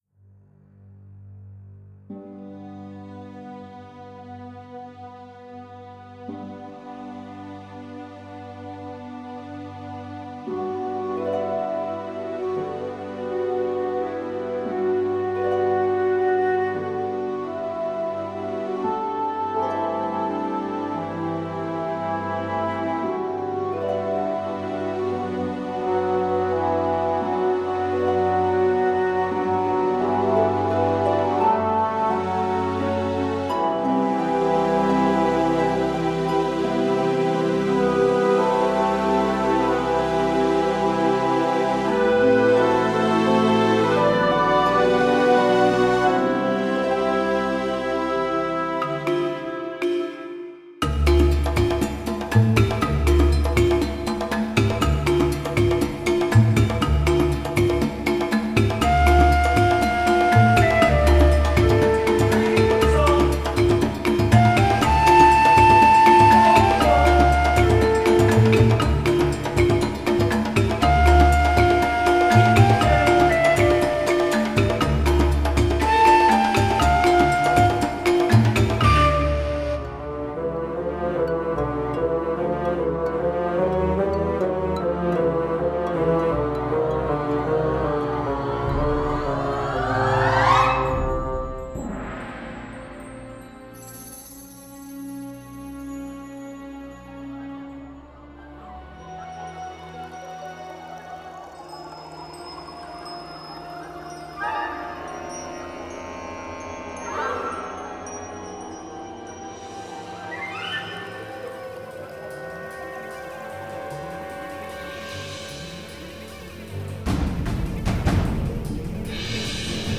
romantico, fantasia, azione, suspense.